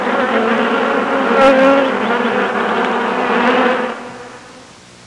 Bee Swarm Sound Effect
Download a high-quality bee swarm sound effect.
bee-swarm-1.mp3